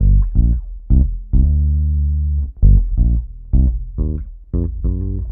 Bass 31.wav